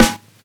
Snares
gah_snr.wav